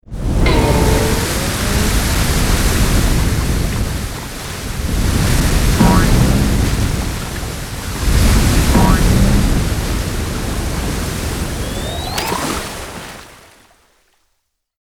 OZ_Geyser.ogg